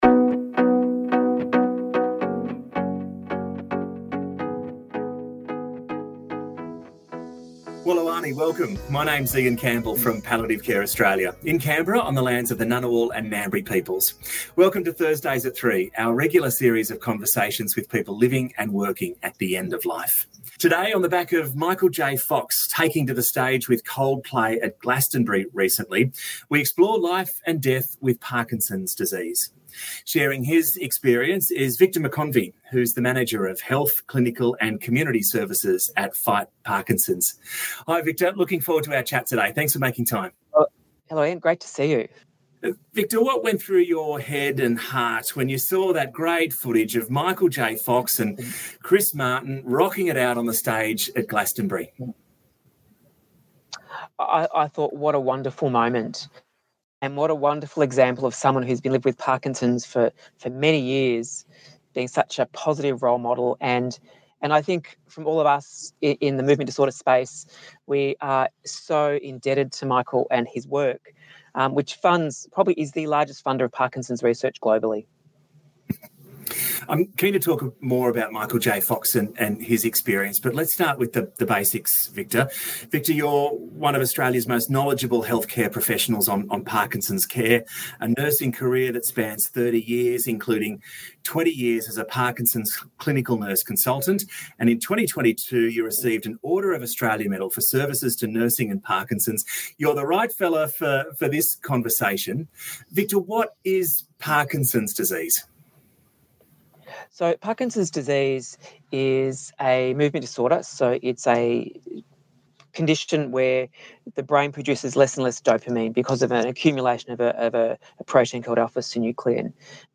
Welcome to Thursdays@3 our regular series of conversations with people living and working at the end of life.